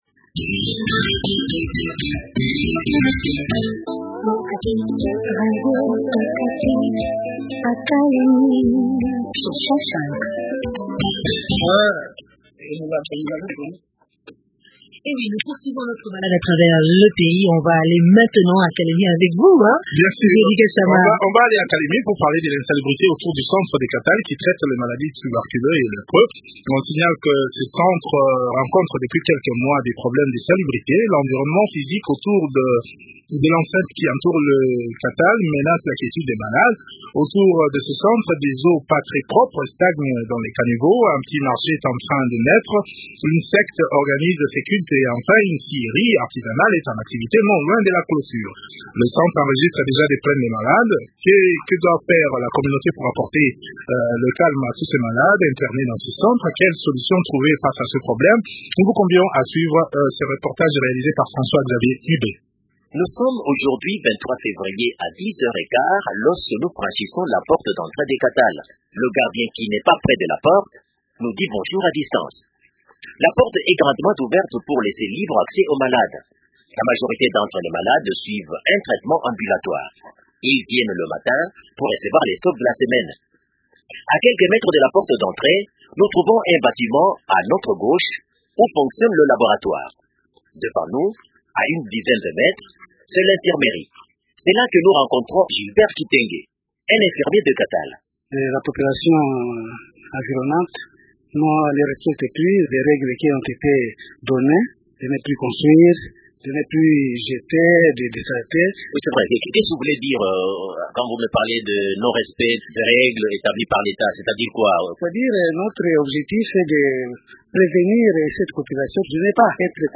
Il est au téléphone